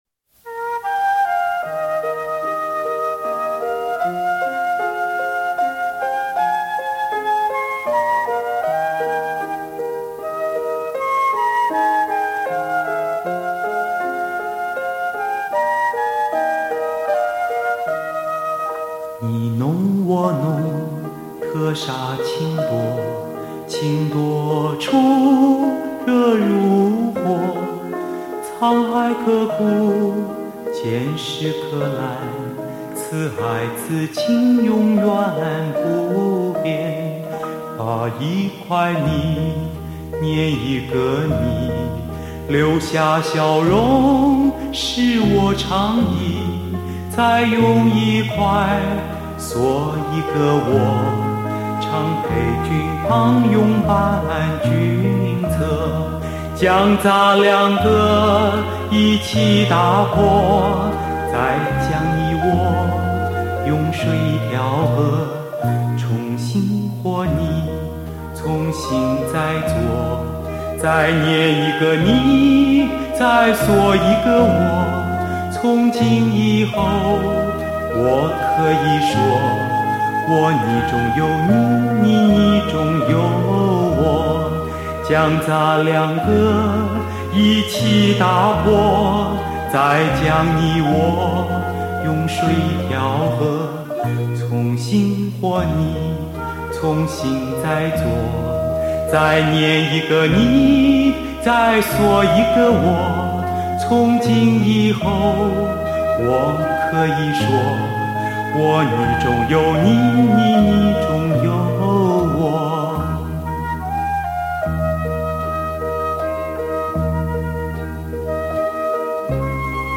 LP